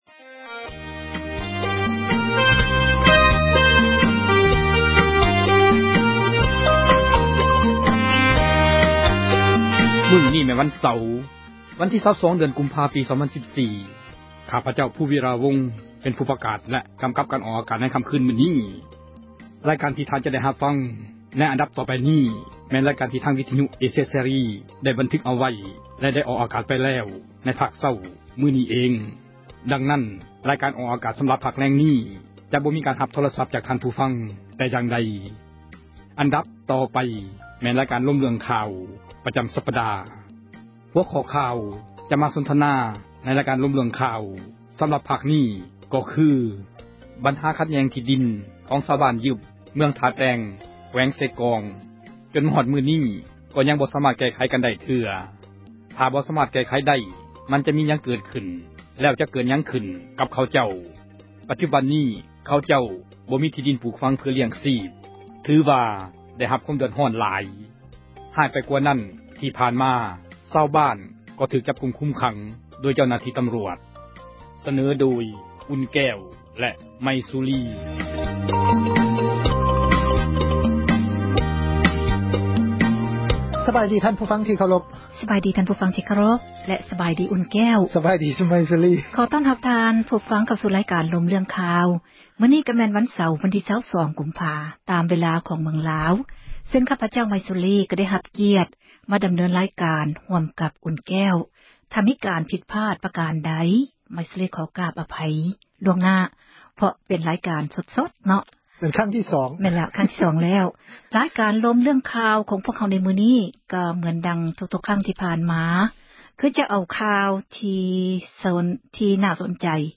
ຂໍ້ຂັດແຍ່ງ ທີ່ີດິນ ຢູ່ບ້ານຍຶບ ແຂວງ ເຊກອງ ຍັງບໍ່ ສາມາດ ແກ້ໄຂໄດ້ ຈົນຮອດ ທຸກມື້ນີ້ ກໍຍັງ ແກ້ໄຂ ບັນຫານີ້ ບໍ່ໄດ້ ຖ້າບໍ່ ສາມາດ ແກ້ໄຂ້ໄດ້ ຈະມີຫຍັງ ເກິດຂຶ້ນ? ເຊີນທ່ານ ຟັງການ ສົນທະນາ ຂອງ ພວກເຮົາໄດ້.